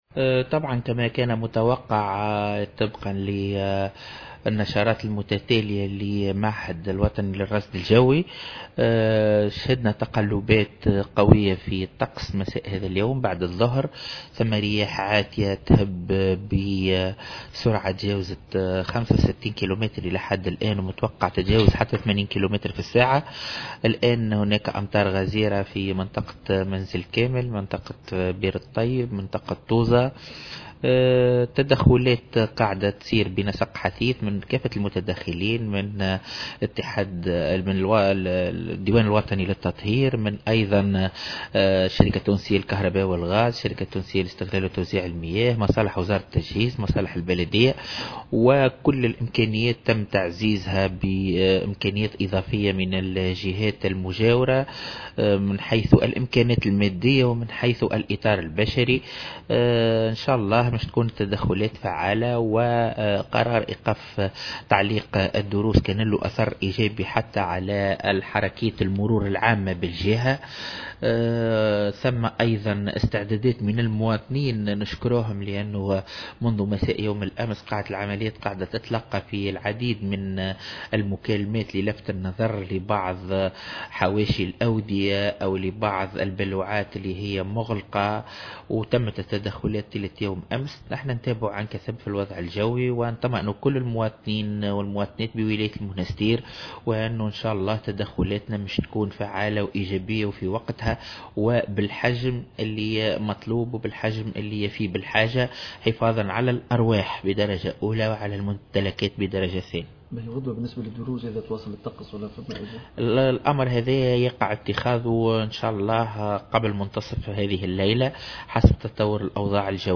ووصف الوالي ورئيس اللجنة الجهوية لمجابهة الكوارث، في تصريح لمراسل الجوهرة أف أم، قرار تعليق الدروس بالمؤسسات التربوية بالجهة بالإيجابي، خاصة على مستوى تسهيل حركة المرور بالجهة، مشيدا في الآن ذاته بتفاعل المواطنين عبر اتصالاتهم المكثفة للفت نظر السلط إلى بعض المخاطر المتعلقة بحواشي الأودية والبالوعات.